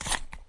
削铅笔机 " 削铅笔机 1
Tag: 每天的生活中 办公室 学校 铅笔